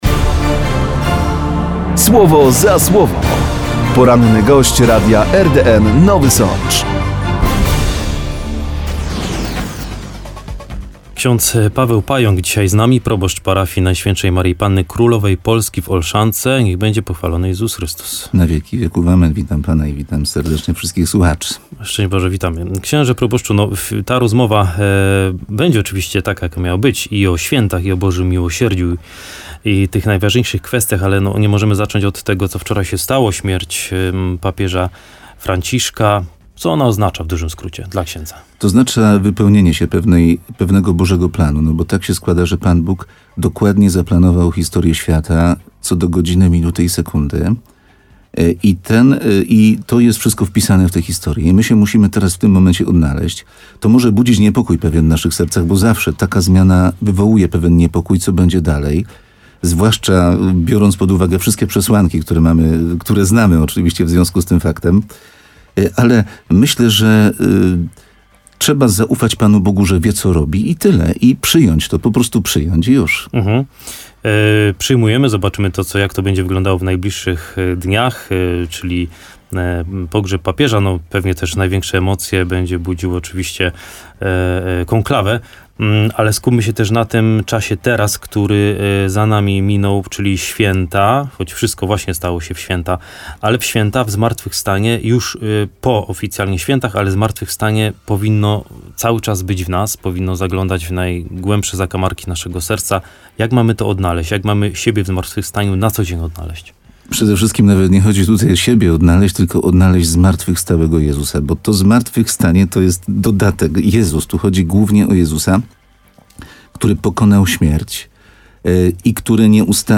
Gość programu Słowo za Słowo w radiu RDN Nowy Sącz odnosił się do świąt Wielkiej Nocy i trwającej właśnie oktawy.